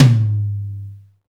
TOM XTOMML0F.wav